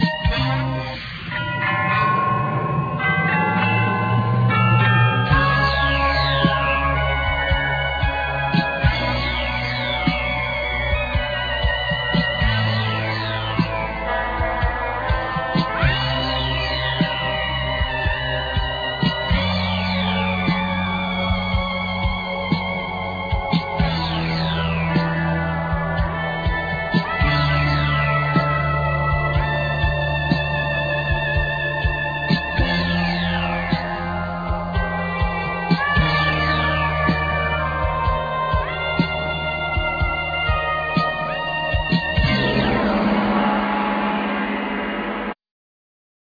Keyboards
Drums,Percussion
Guitar,sitar
Bass,Backing Vocal
Trumpet
Trombone,Backing Vocal
Tenor saxophne,Flute
Alto and Soprano saxophone
Violin
Lead vocal
Duduk
Synthesizer,Dutar & Lead vocal